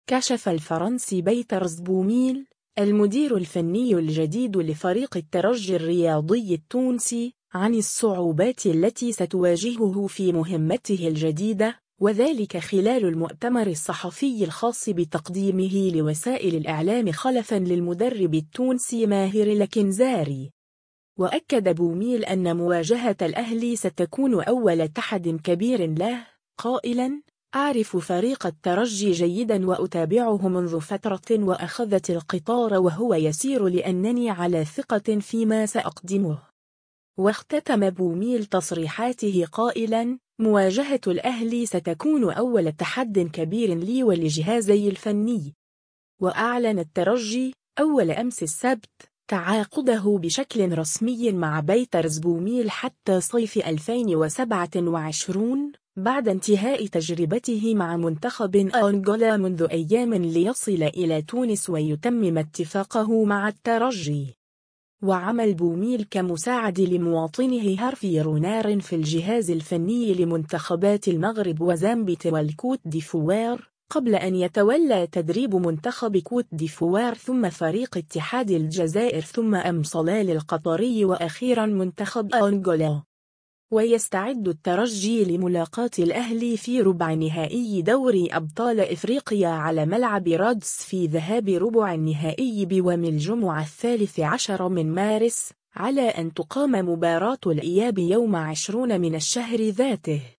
كشف الفرنسي باتريس بوميل، المدير الفني الجديد لفريق الترجي الرياضي التونسي ، عن الصعوبات التي ستواجهه في مهمته الجديدة، و ذلك خلال المؤتمر الصحفي الخاص بتقديمه لوسائل الإعلام خلفا للمدرب التونسي ماهر الكنزاري.